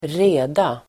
Uttal: [²r'e:da]